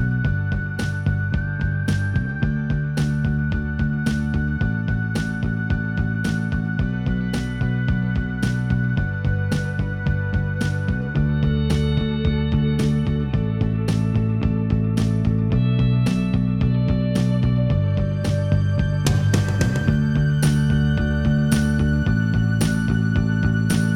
Minus Lead Guitar Indie / Alternative 4:52 Buy £1.50